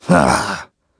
Clause_ice-Vox_Sigh.wav